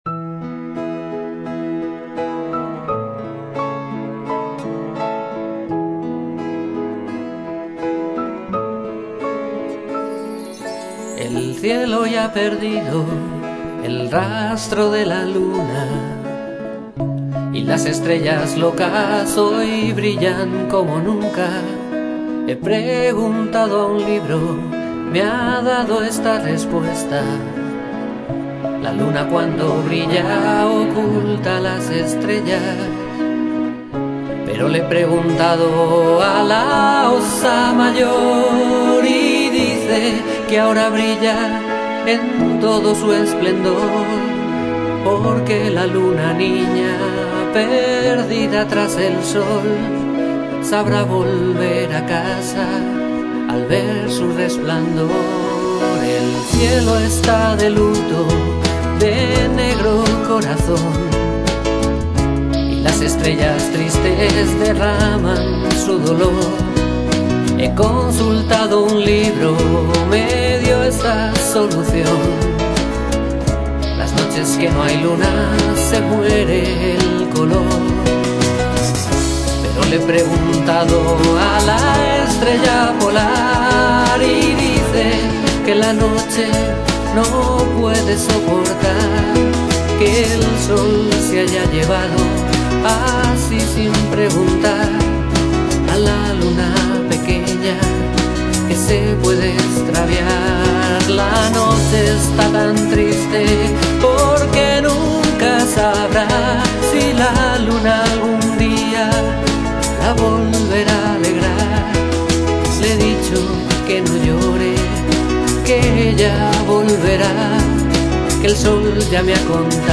Cantautor madrileño